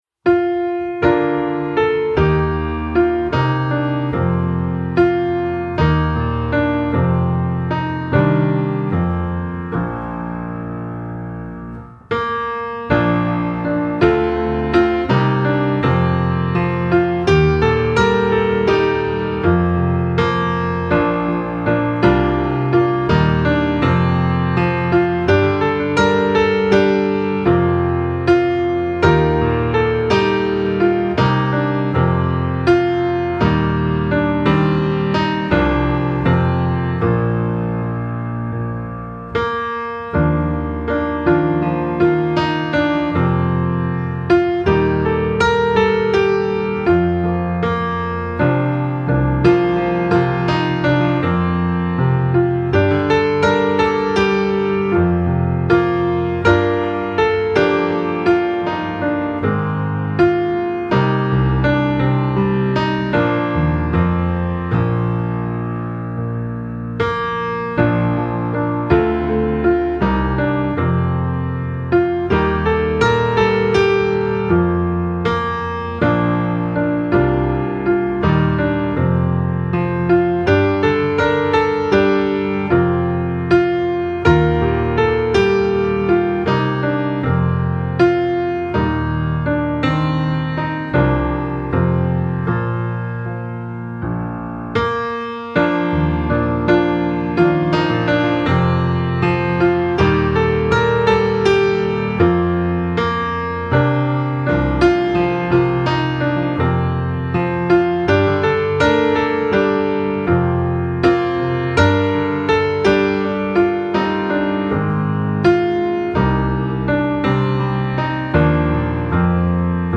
Hymn
fe46d-comefollowmethesaviorspake-pianoonly.mp3